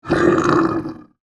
Bear Growl Fx Bouton sonore